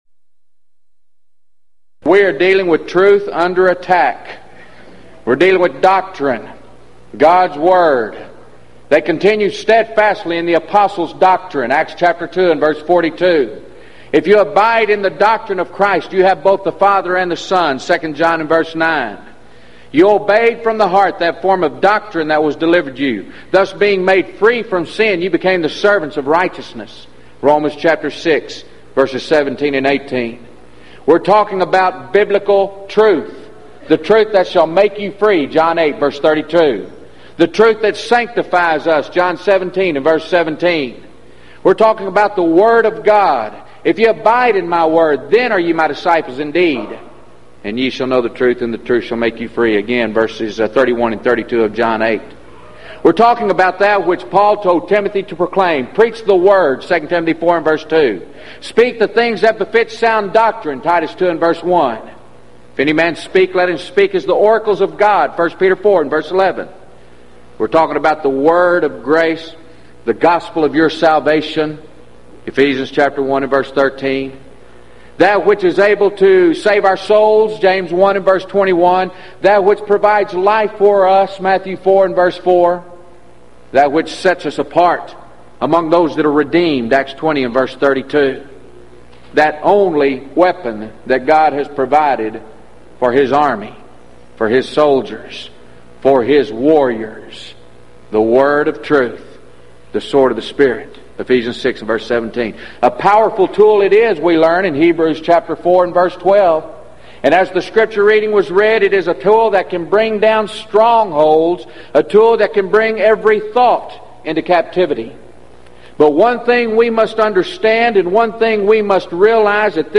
Event: 1996 Gulf Coast Lectures
If you would like to order audio or video copies of this lecture, please contact our office and reference asset: 1996GulfCoast08